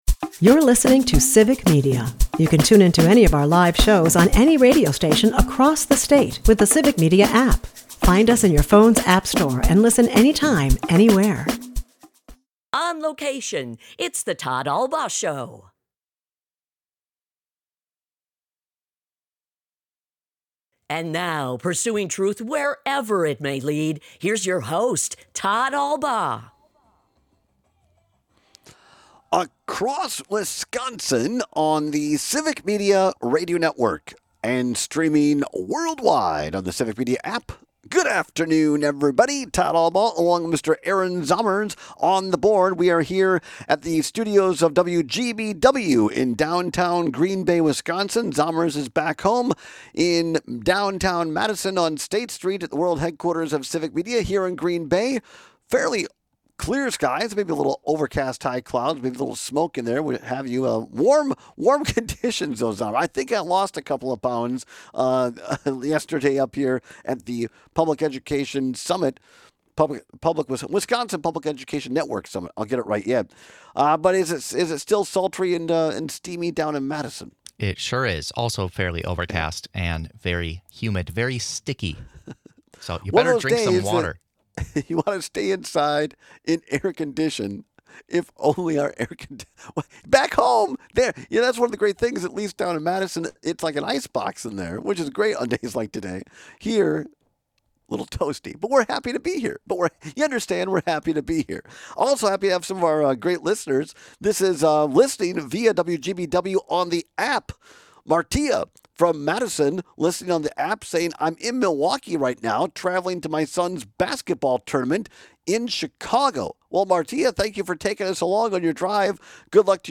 We take your calls and texts about At the bottom of the hour